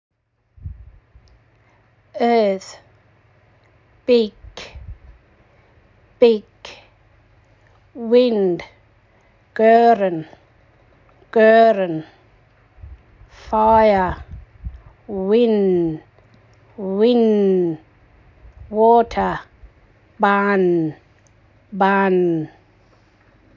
As so, we have translated our House Team names to the language of our true history of ENPS. Play this audio file below to hear how to pronounce each team name.